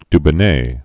(dbə-nā, dy-)